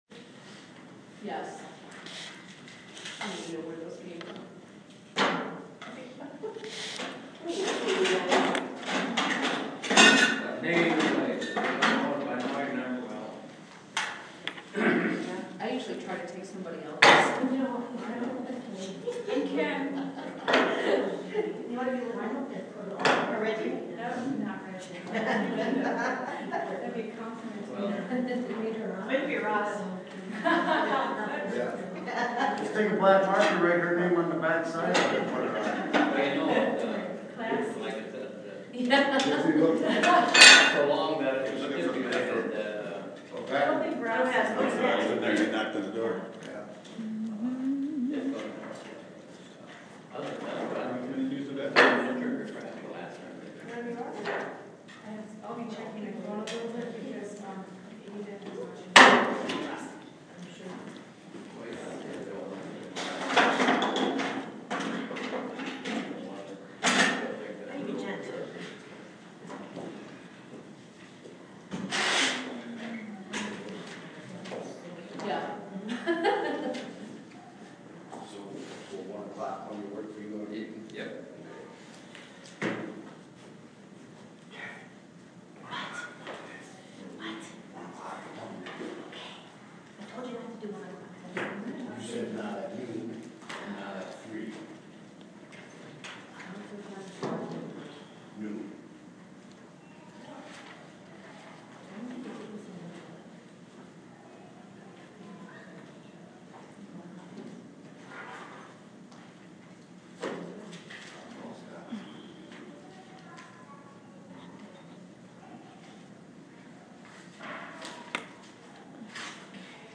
VILLAGE OF ELBERTA BOARD OF TRUSTEES REGULAR MEETING